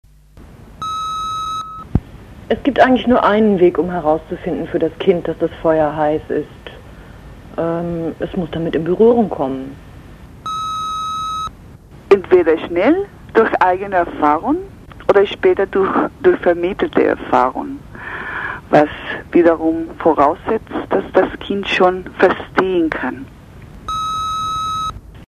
Klanginstallationen - Ausschnitte im mp3-Format